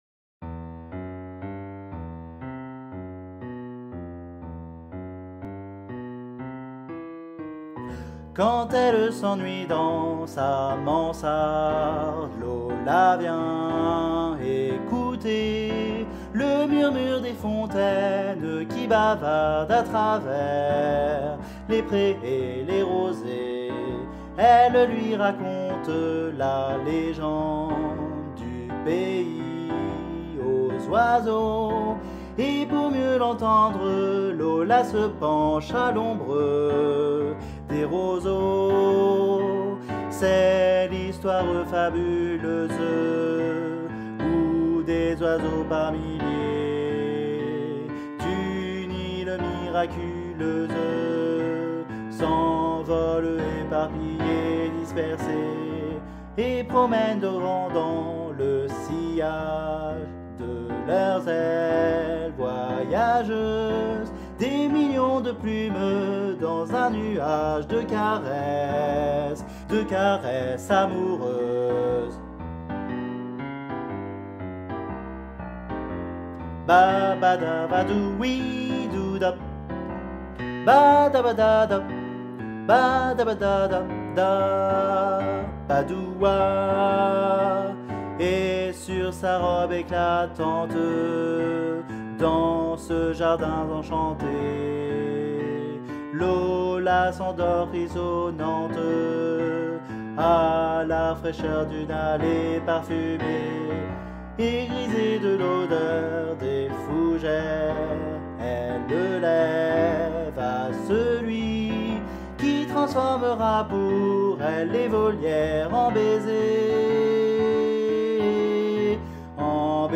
MP3 versions chantées
Guide Voix Altos